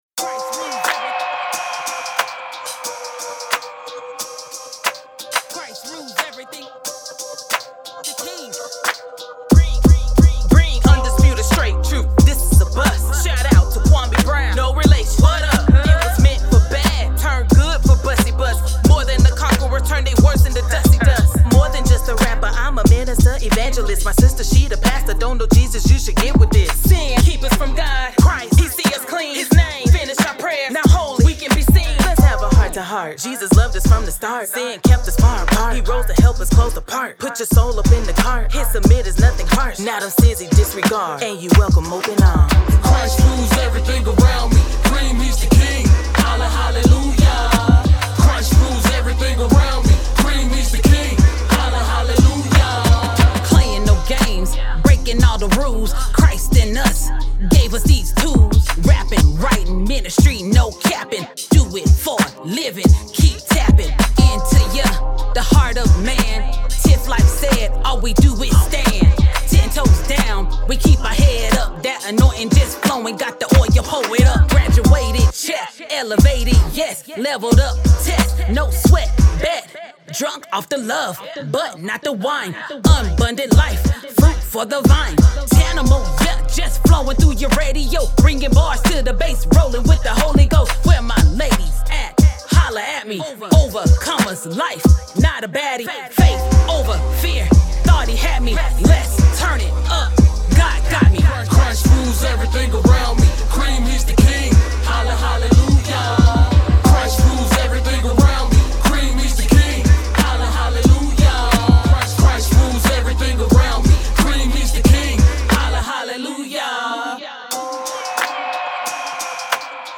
of the Christian Hip Hop duo